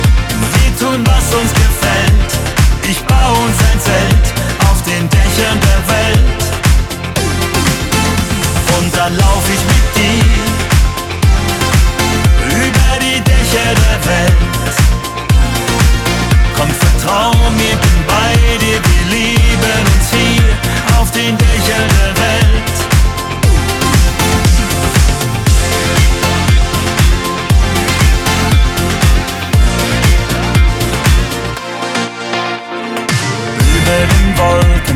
Жанр: Поп музыка
German Pop